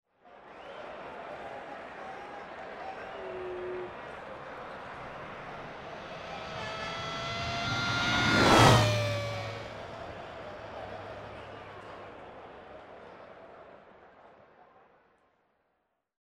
Pasada de un coche en una carrera de formula 3000
Sonidos: Deportes